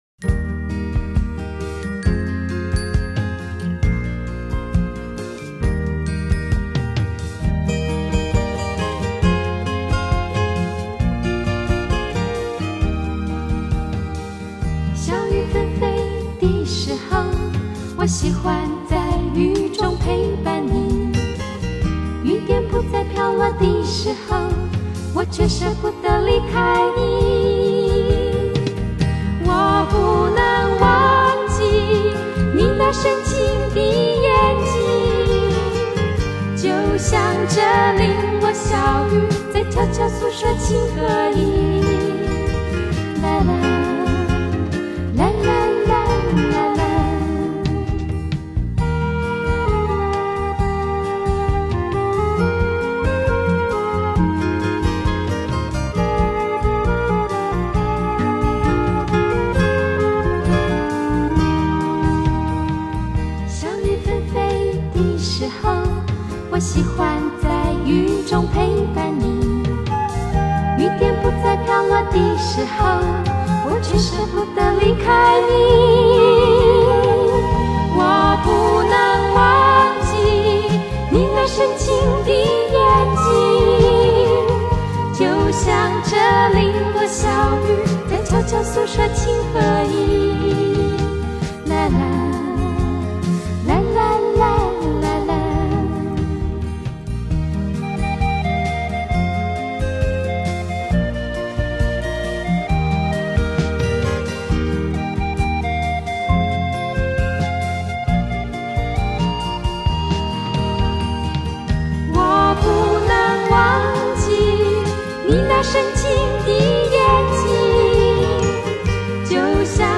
地　　区：台湾
八张黄金版CD之100首曲目，全以最新24位元数位录音技术处理，重现当年歌手原声重唱，音色更为甘亮饱满。